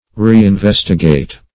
Search Result for " reinvestigate" : The Collaborative International Dictionary of English v.0.48: Reinvestigate \Re`in*ves"ti*gate\ (-v?s"t?*g?t), v. t. To investigate again.
reinvestigate.mp3